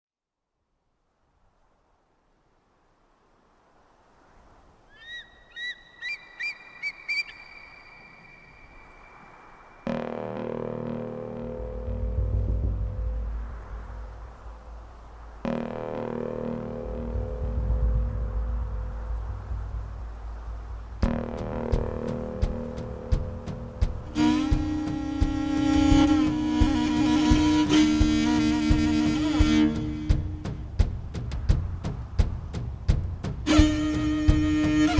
# Metal